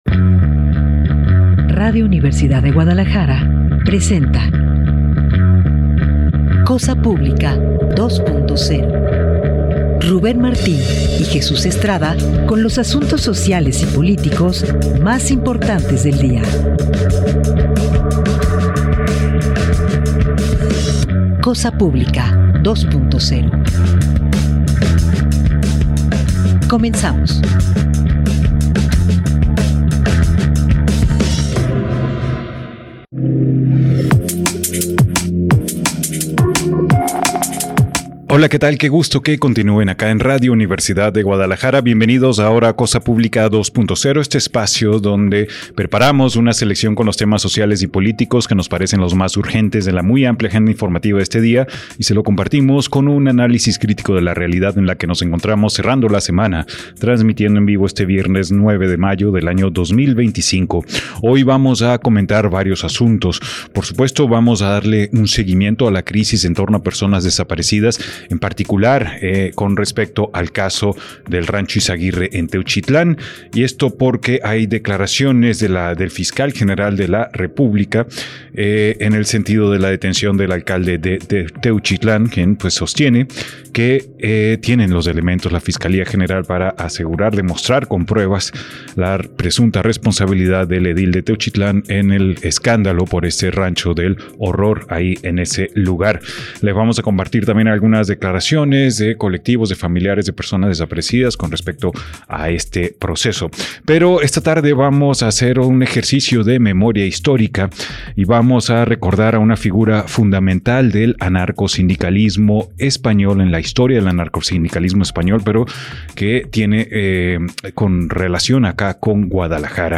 en cabina 3.